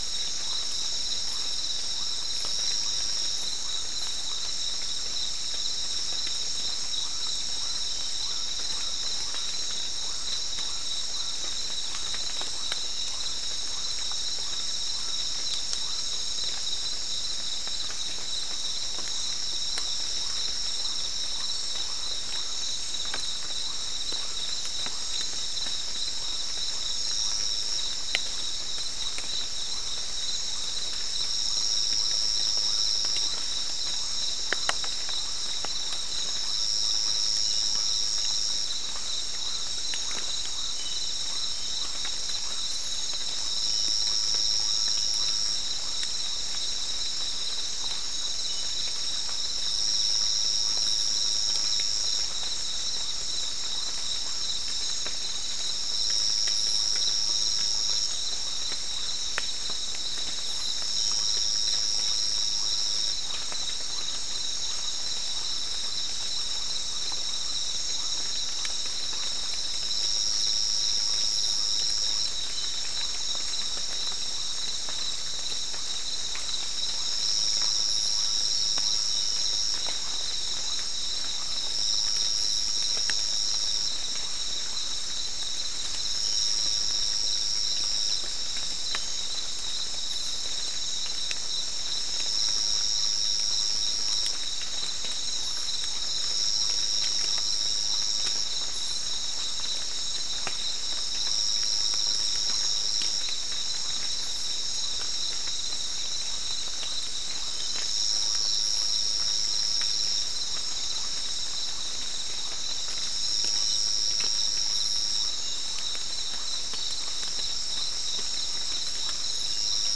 Soundscape
Recorder: SM3